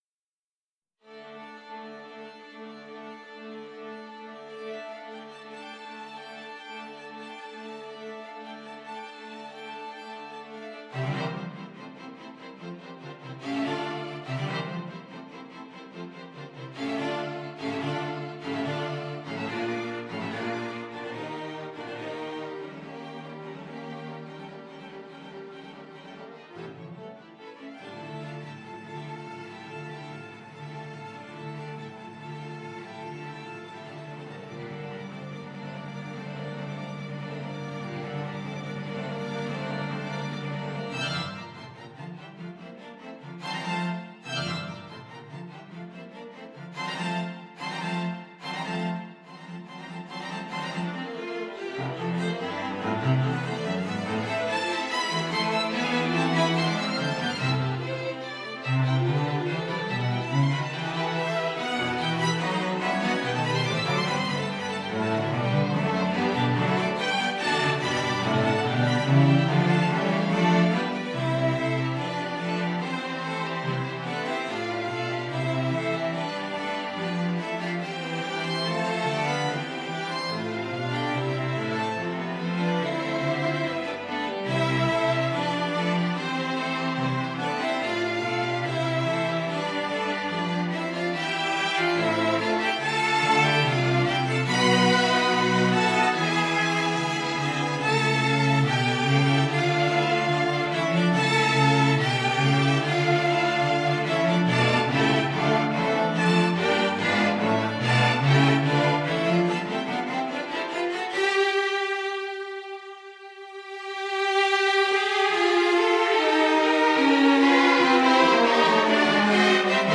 Amongst other changes, Tchaikovsky rewrote the second subject in the finale. The original version can be heard from 1'11 to 2'07 and 4'44 to 5'18 in this reconstruction. The original ending (from 6'33) was also slightly shorter than in the revised version.